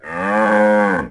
moo2.wav